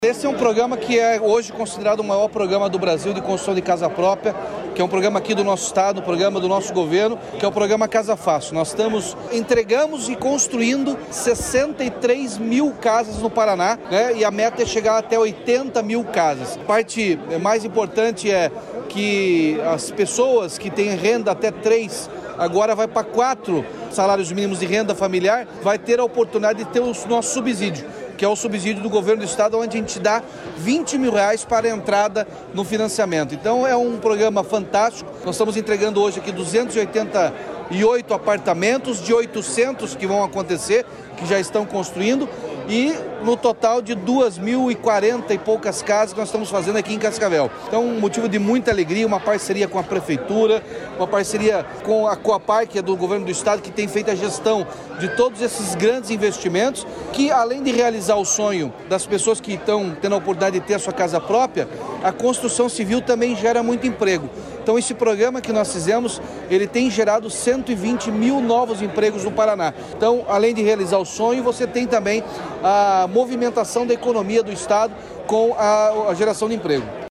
Sonora do governador Ratinho Junior sobre a entrega de moradias para 85 famílias de Cascavel